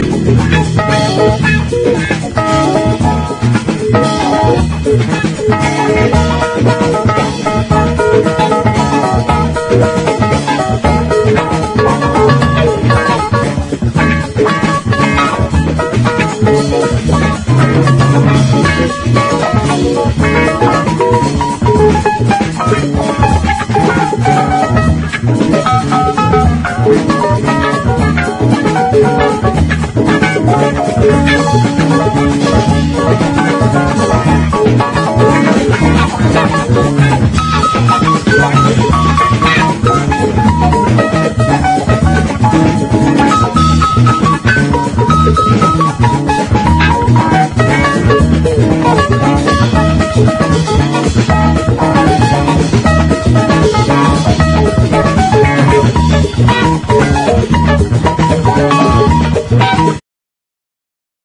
ROCK / 70'S / MODERN POP
再発盤/ 壮大なオーケストラ・アレンジとビートリッシュなメロディーが溶け合った6THアルバム！